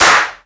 Clap
Original creative-commons licensed sounds for DJ's and music producers, recorded with high quality studio microphones.
Clap Sample F Key 16.wav
clap-single-shot-f-key-25-ZwN.wav